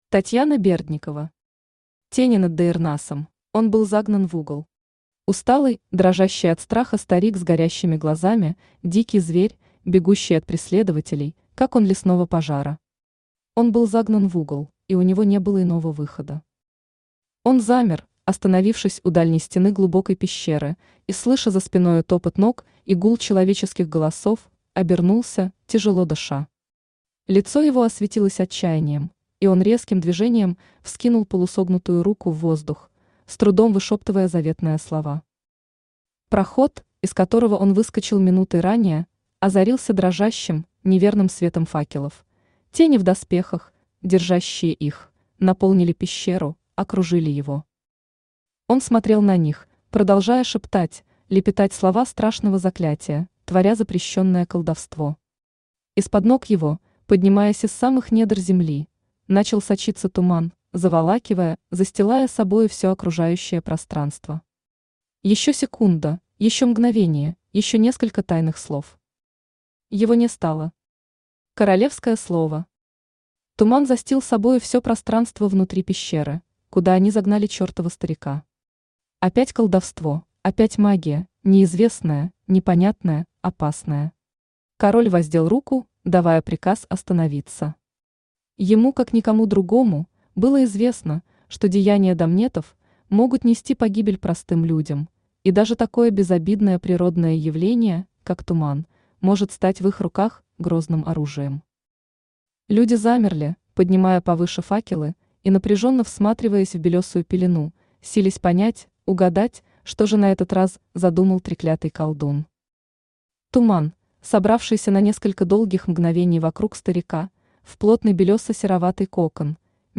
Аудиокнига Тени над Даирнасом | Библиотека аудиокниг
Aудиокнига Тени над Даирнасом Автор Татьяна Андреевна Бердникова Читает аудиокнигу Авточтец ЛитРес.